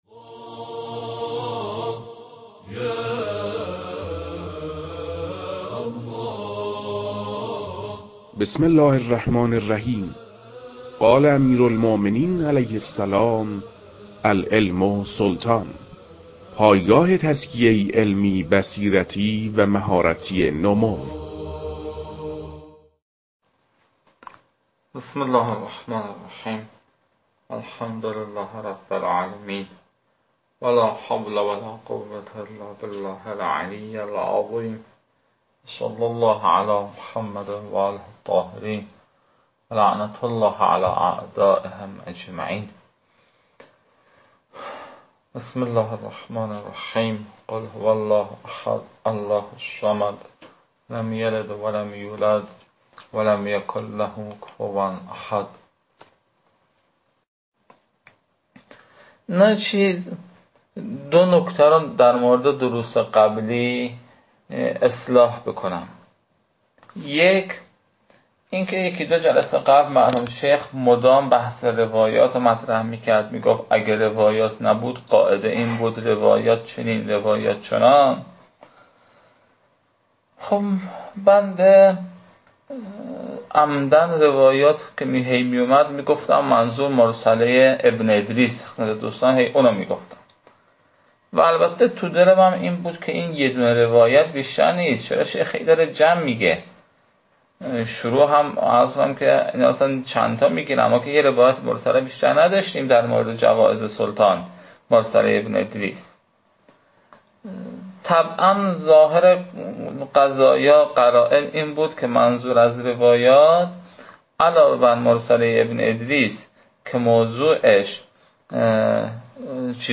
فایل های مربوط به تدریس مبحث المسألة الثانية جوائز السلطان و عمّاله از خاتمه كتاب المكاسب